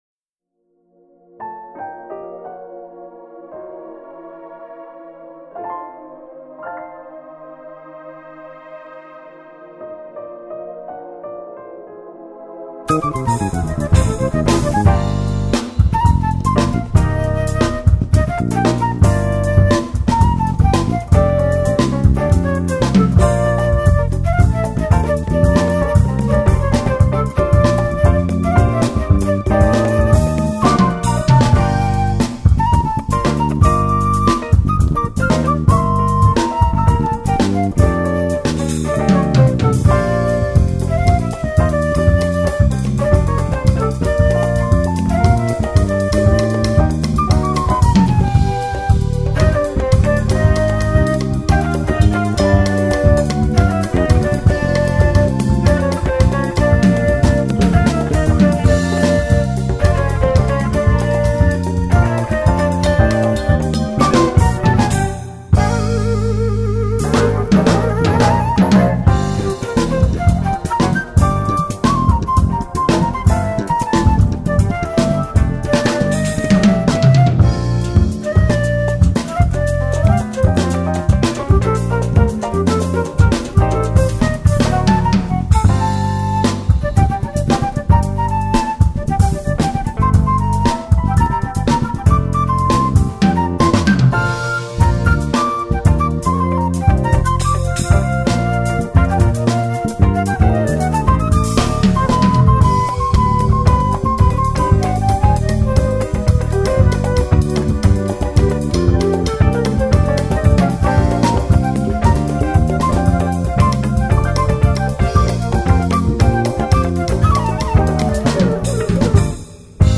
2539   04:41:00   Faixa: 3    Jazz